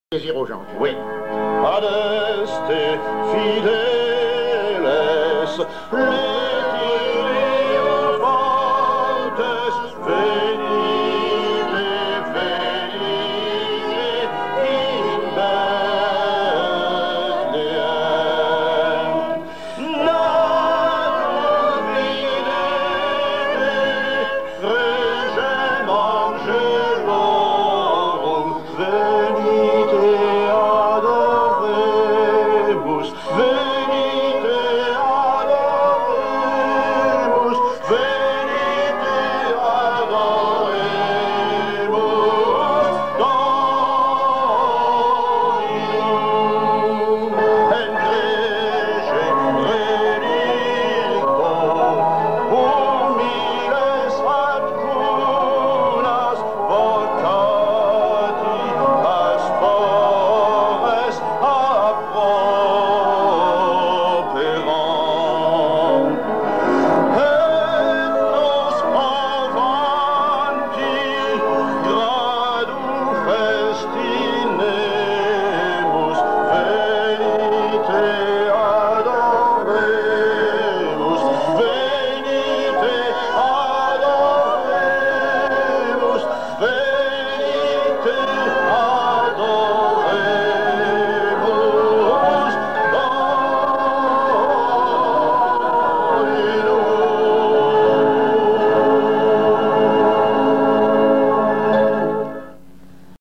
hymne chrétien pour le temps de Noël
cantique
Genre strophique
Pièce musicale inédite